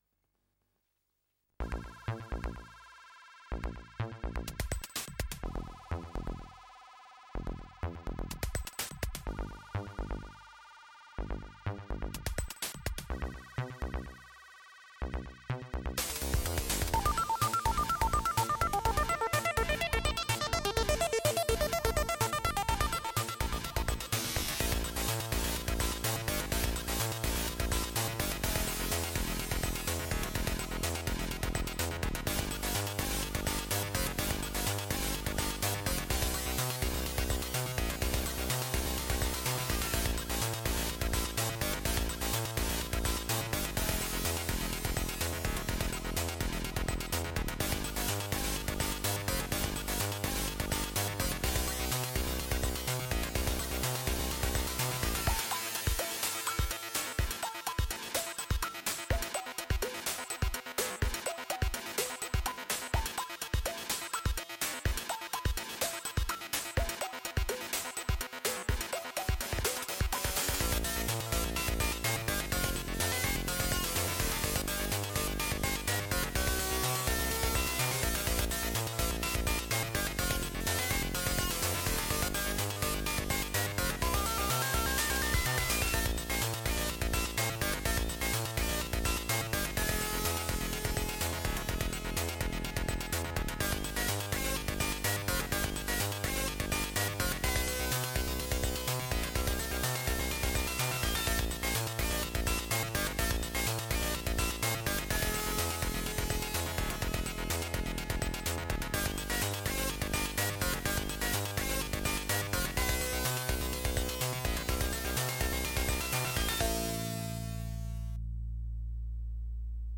Ok, gotowe,  to znaczy dzialajace u mnie, FM gra tez junormalnie , prawde mowiac szczeka mi opadla co z timexa wydobylem.